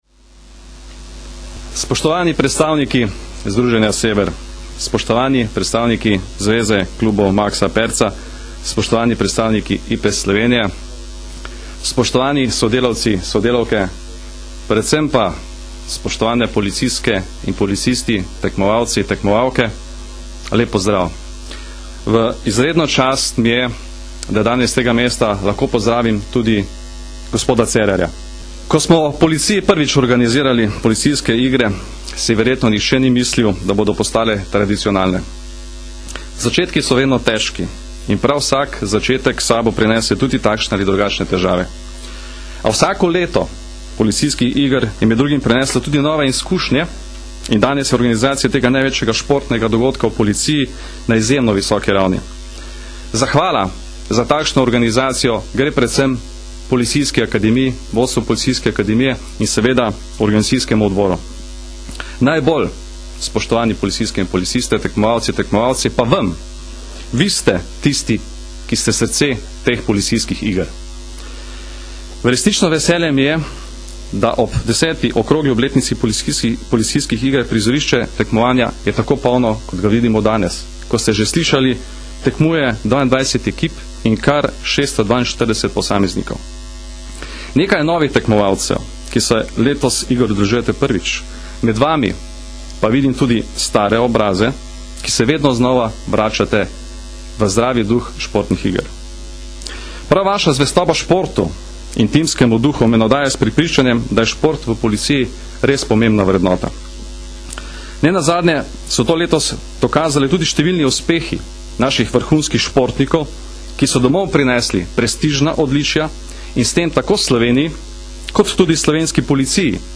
Danes, 26. maja 2011, jih je v športnem parku Policijske akademije slavnostno odprl generalni direktor policije Janko Goršek.
Zvočni posnetek nagovora generalnega direktorja policije in predsednika glavnega organizacijskega odbora 10. policijskih iger Janka Gorška (mp3)
Nagovor